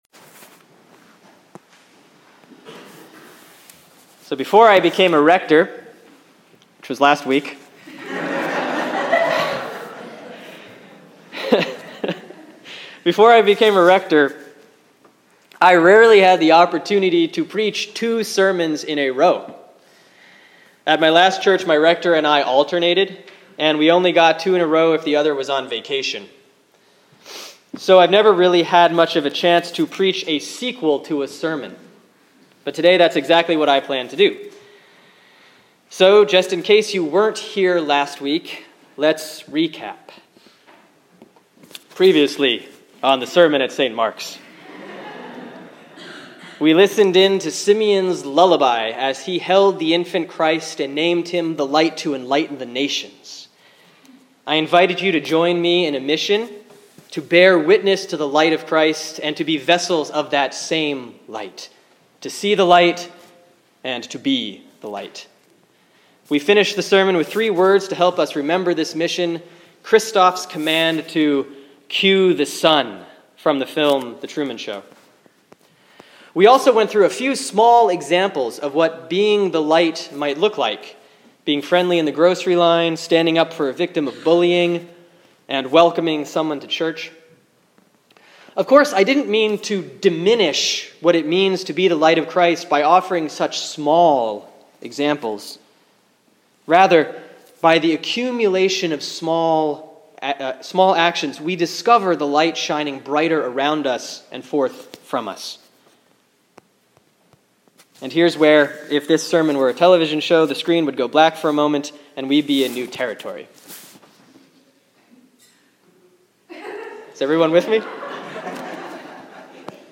Sermon for Sunday, February, 16, 2014 || Epiphany 6A || Deuteronomy 30:15-20; Matthew 5:21-37